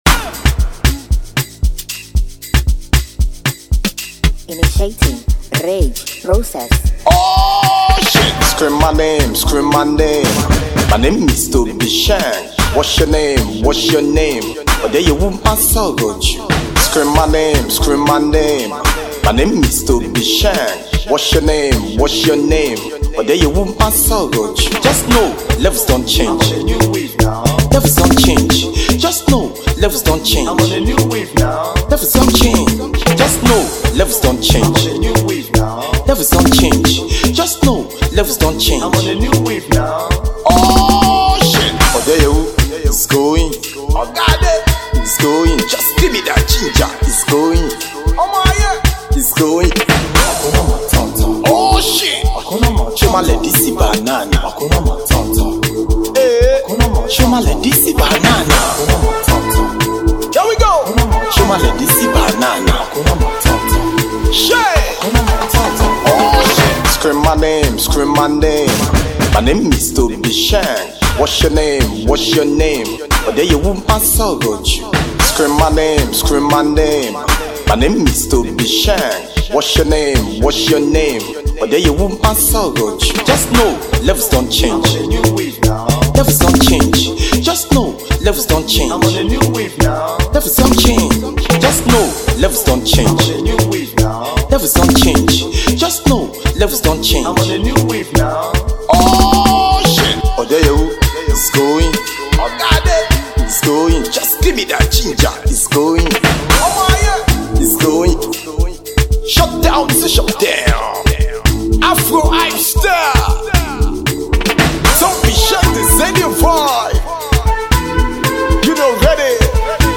a Nigerian hype MC, and musician